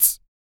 Index of /90_sSampleCDs/ILIO - Vocal Planet VOL-3 - Jazz & FX/Partition H/1 MALE PERC
HIHAT 18.wav